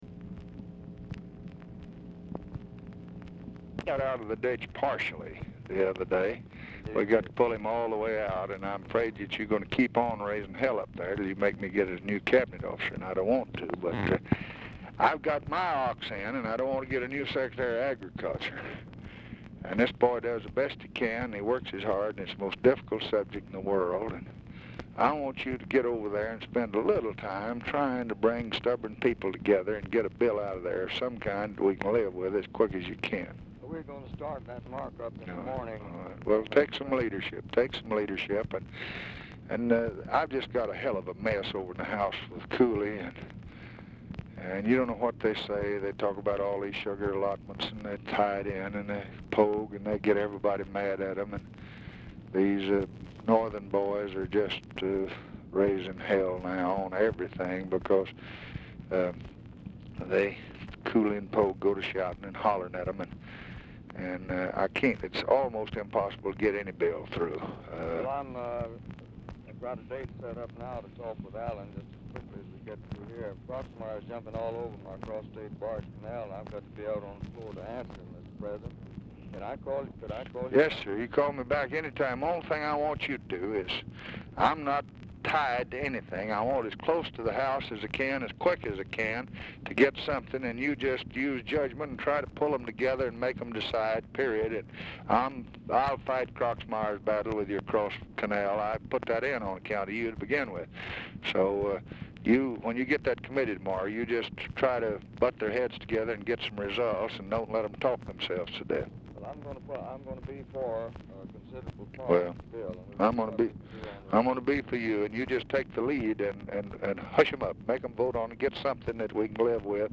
Telephone conversation
RECORDING STARTS AFTER CONVERSATION HAS BEGUN
Dictation belt